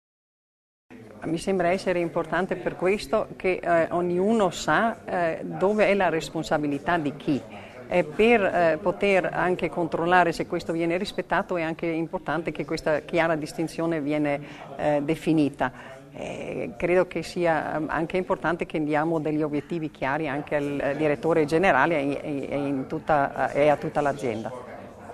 nel corso di una conferenza stampa, a Palazzo Widmann